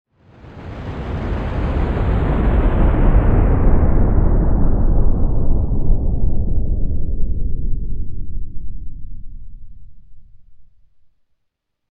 Massive Slowing Down Sound Effect
A deep, slow-down sound effect drops in pitch and creates epic tension. The low whoosh with reverb works well for trailers, action scenes, sci-fi, and horror moments. You hear dramatic slow-motion impact perfect for cinematic projects and time-freeze effects.
Massive-slowing-down-sound-effect.mp3